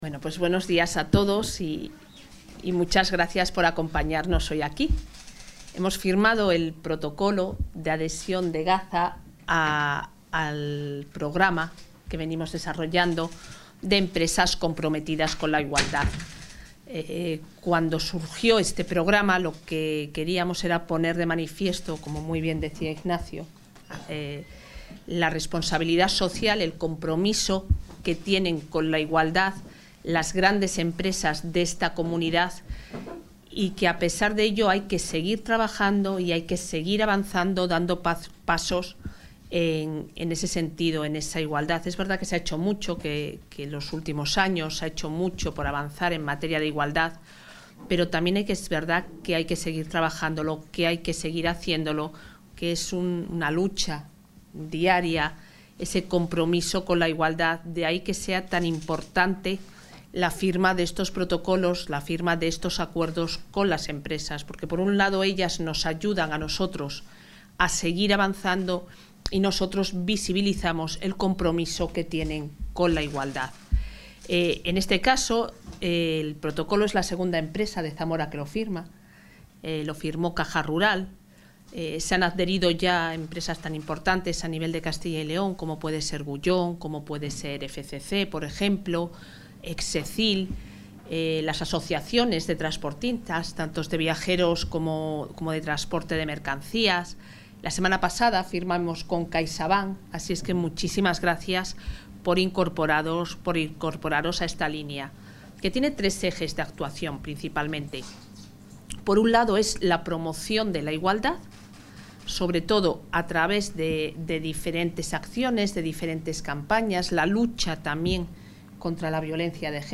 Intervención de la consejera.